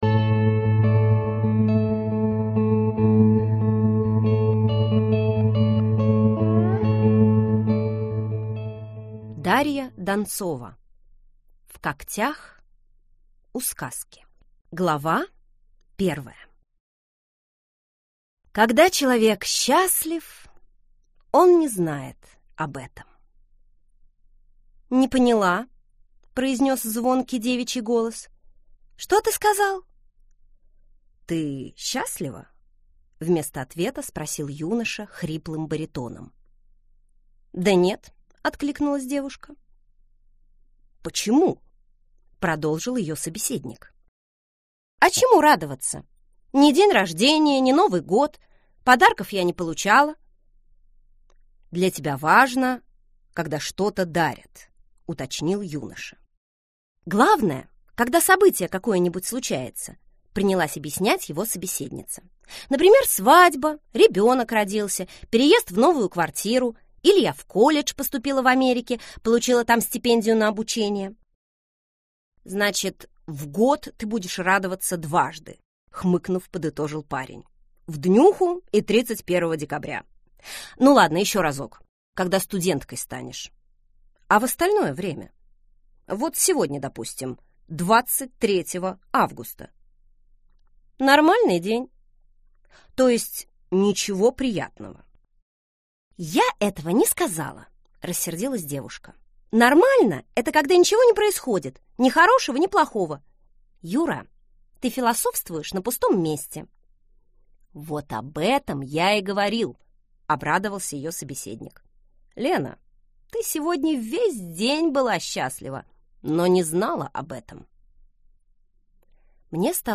Аудиокнига В когтях у сказки - купить, скачать и слушать онлайн | КнигоПоиск